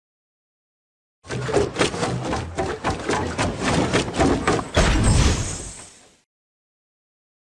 Sound_FinishBuilding.mp3